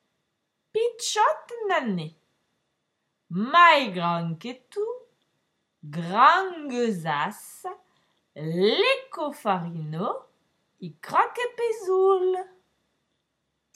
This rhyme is usually recited.